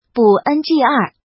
怎么读
ńg